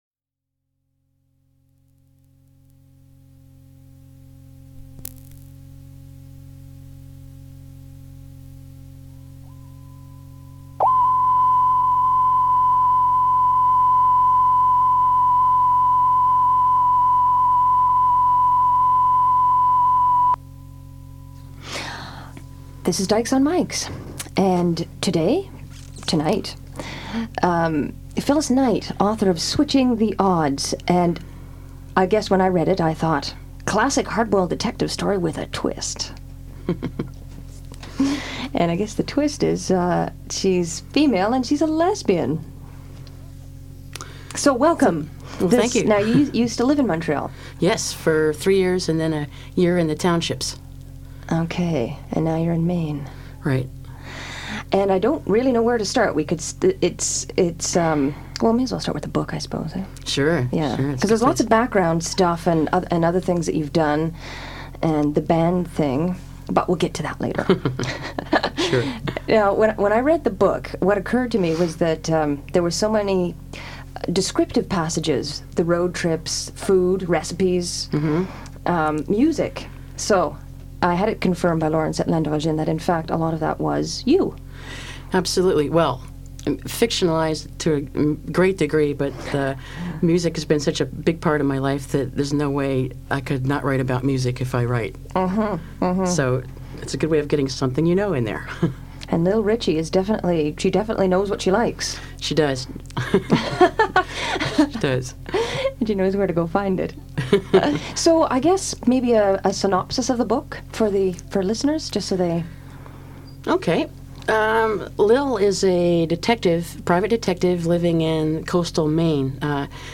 The Dykes on Mykes radio show was established in 1987.